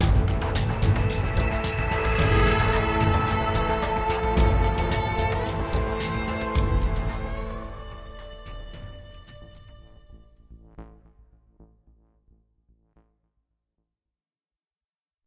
level up music